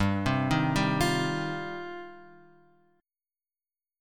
G 7th Suspended 4th Sharp 5th
G7sus4#5 chord {3 3 1 0 x 1} chord